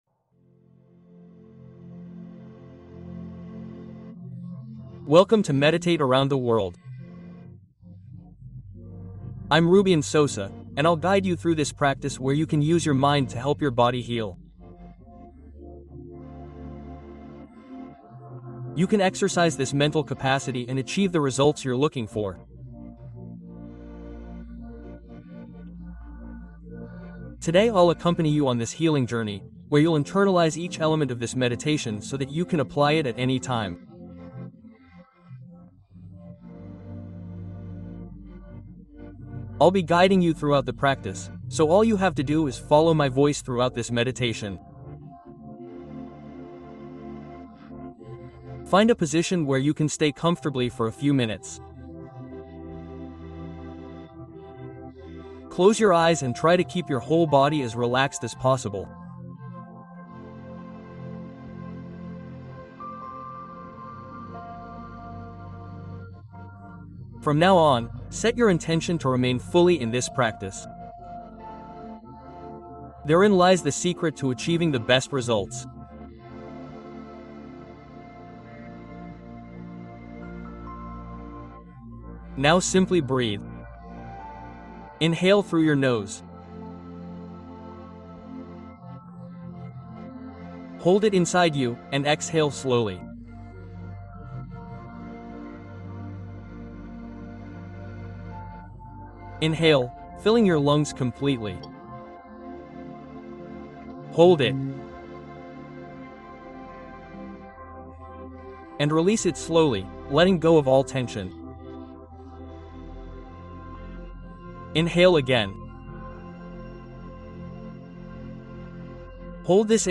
Descanso Nocturno Consciente: Meditación para Dormir con Tranquilidad